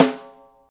gretsch rim f.wav